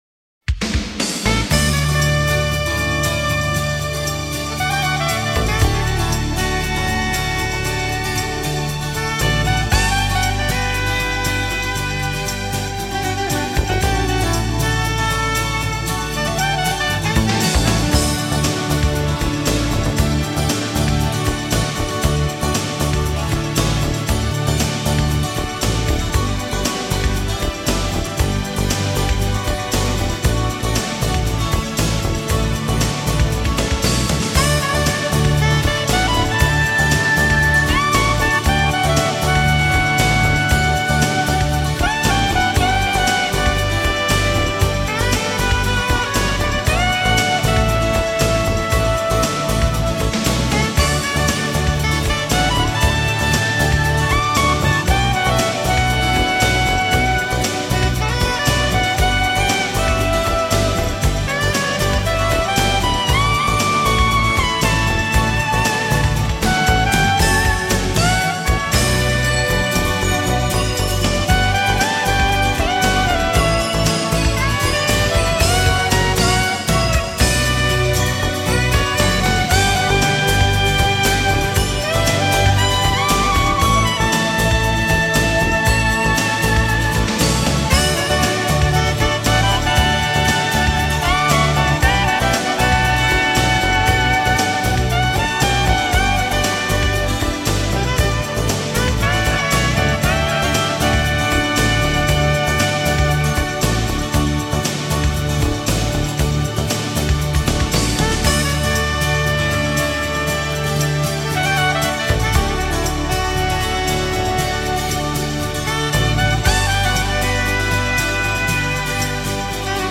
으로 경쾌한 곡 올립니다...